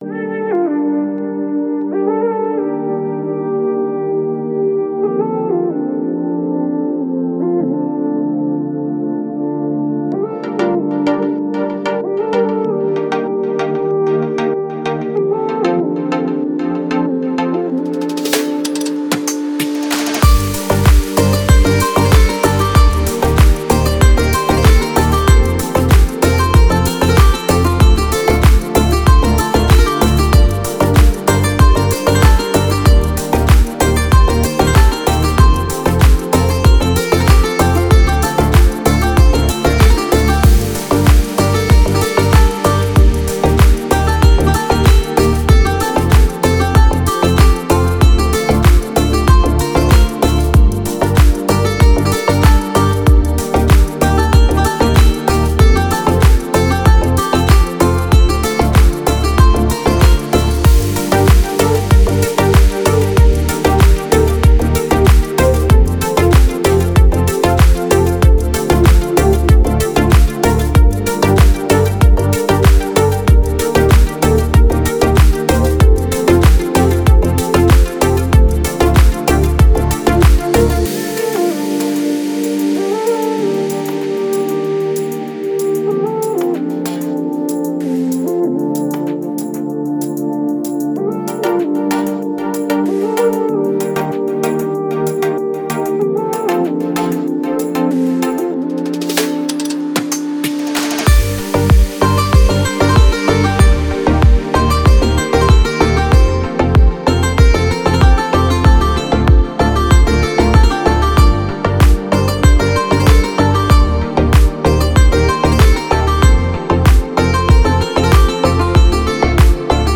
Спокойная музыка
лайтовая музыка